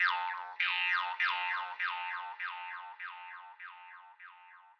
描述：西伯利亚犹太人的竖琴
Tag: 100 bpm Breakbeat Loops Woodwind Loops 826.96 KB wav Key : Unknown